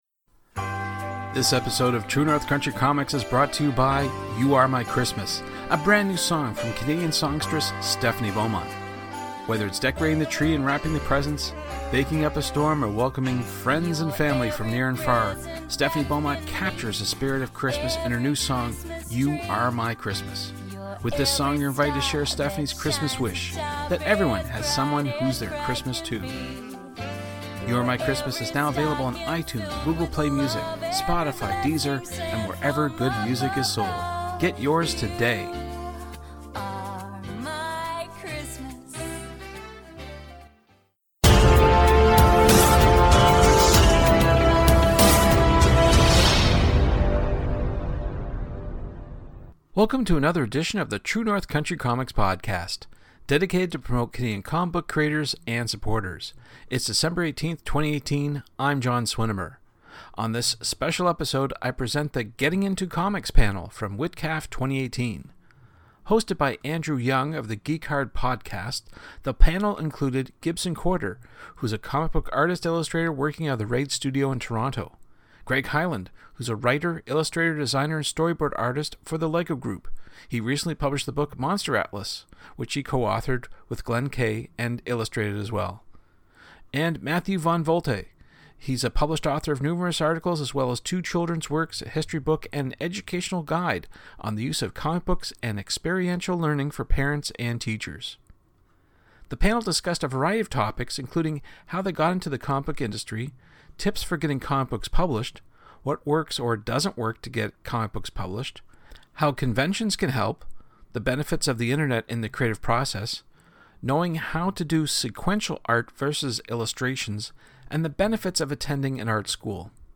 True North Country Comics presents the Getting Into Comics panel from WhitCAF 2018.